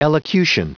Prononciation du mot elocution en anglais (fichier audio)
Prononciation du mot : elocution